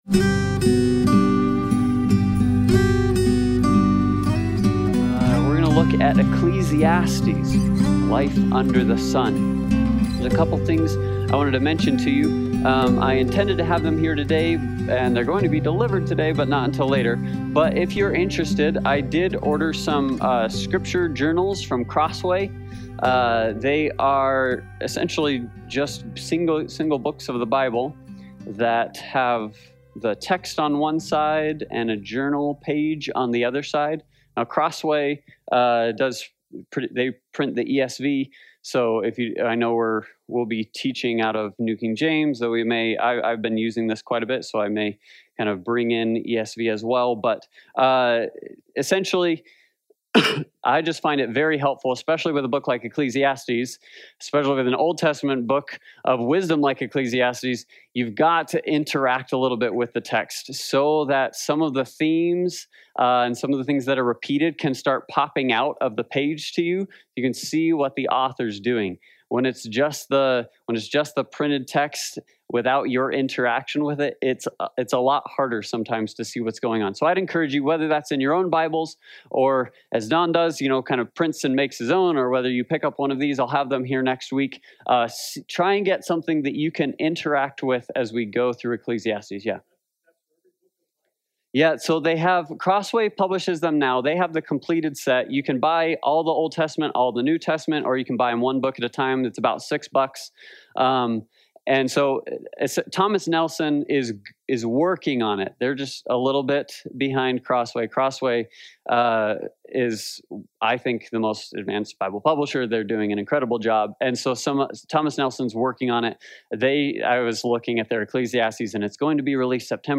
Passage: Ecclesiastes 1:1-3 Service Type: Sunday Bible Study